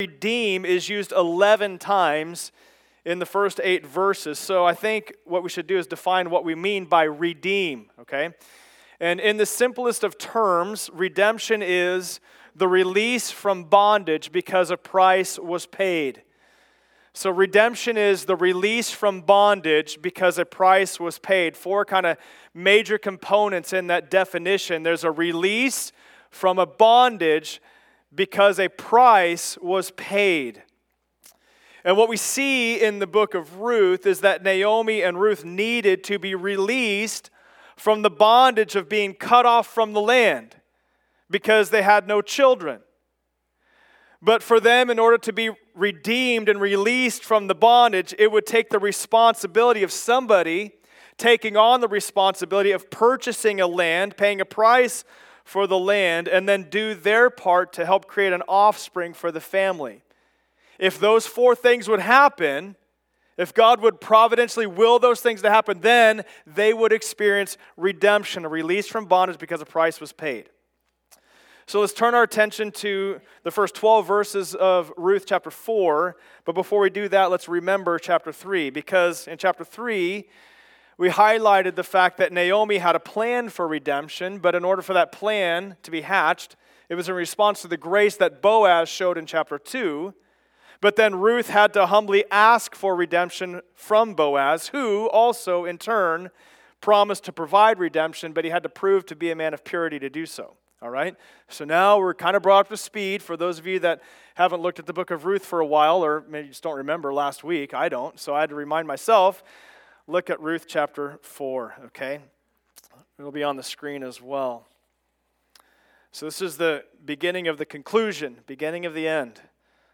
Ruth 4 Service Type: Sunday Service Download Files Notes « Ruth 3